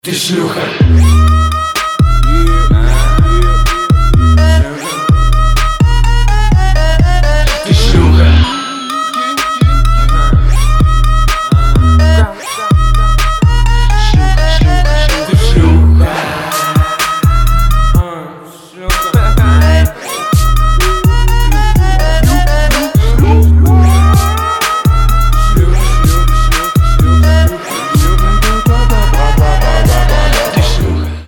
русский рэп
Trap
Bass
нецензурная лексика